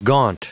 Prononciation du mot gaunt en anglais (fichier audio)
Prononciation du mot : gaunt